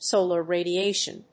音節sòlar radiátion